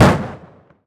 EXPLOSION_Distorted_04_Medium_stereo.wav